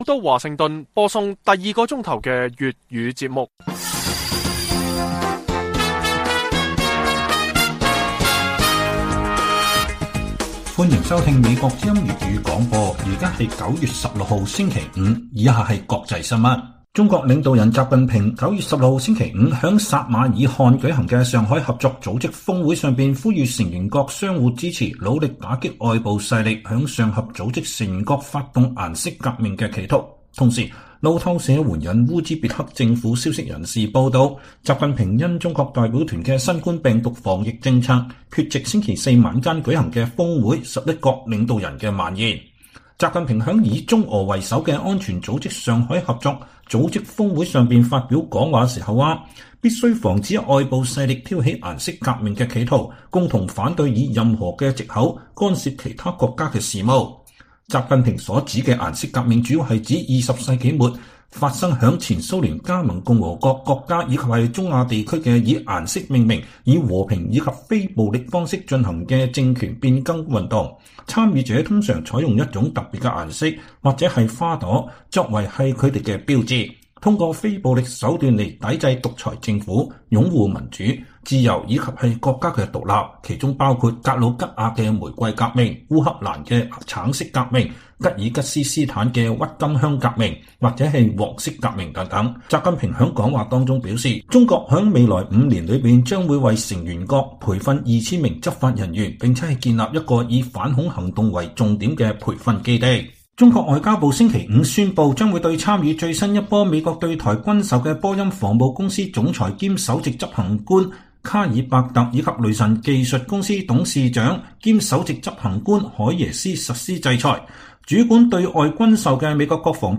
粵語新聞 晚上10-11點: 擔心失去政權習近平促上合組織各國合力打擊“顏色革命”